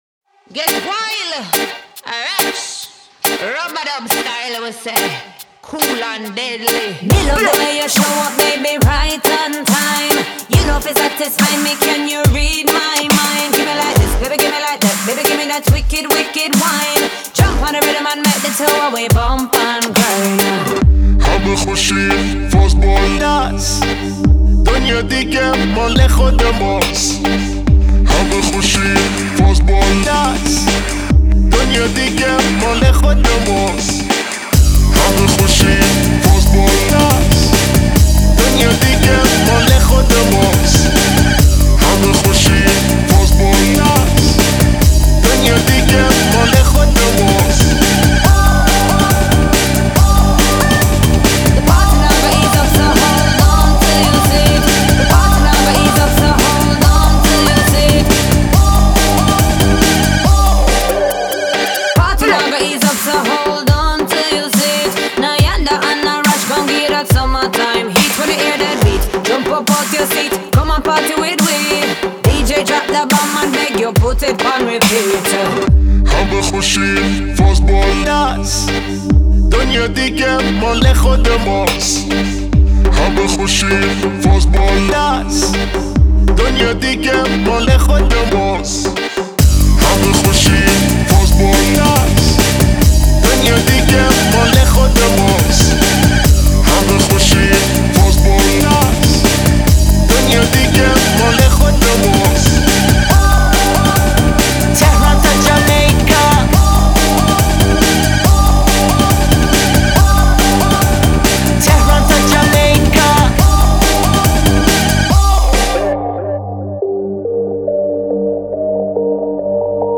это энергичная и зажигательная песня в жанре поп и регги